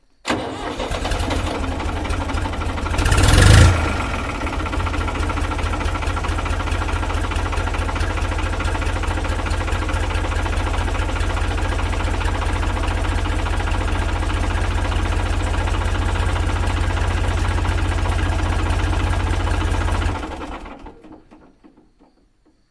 柴油机船4环100307
描述：我从我的现场录音中提取了一个拖网渔船柴油机空转的片段，并使之可以循环播放。
标签： 柴油机 发动机 船舶 渔船
声道立体声